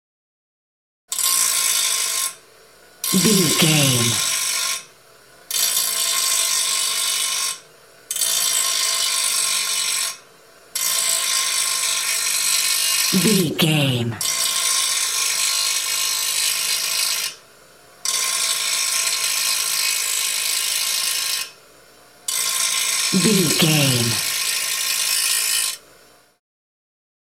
Sound Effects
urban
hard